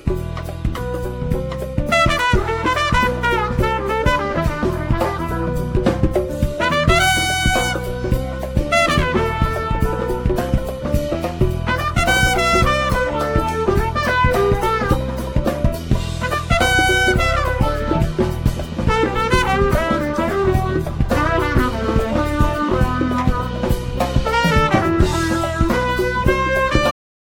trumpet
piano
bass
drums
percussion